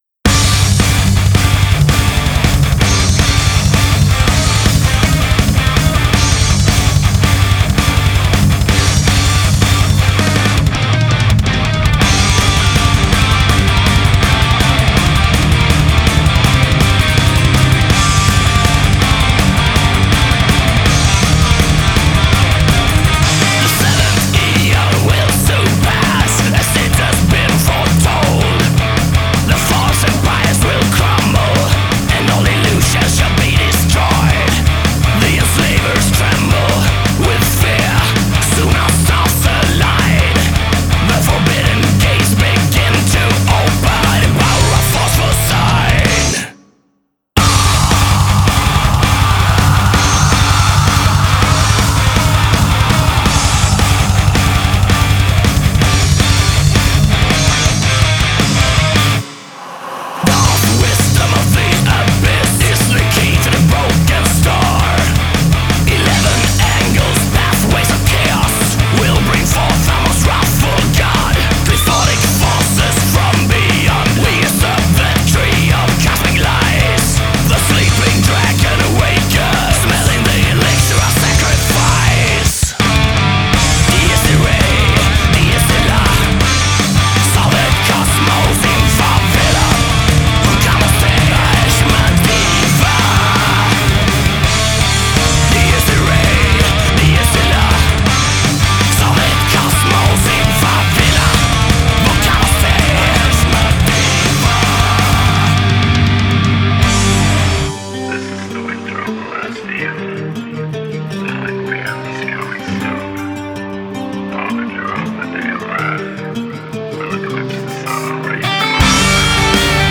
genre: melodic death-black
Melodic Black Metal Melodic Death Metal